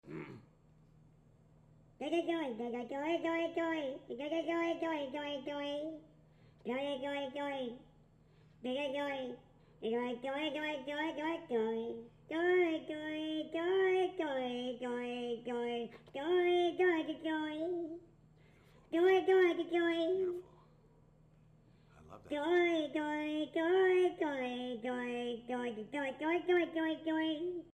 Meme Sound Effects
doidoidoidoi soundboard sound buttons mp3 download free my instant sound buttons online mp3 doidoidoidoi sound effects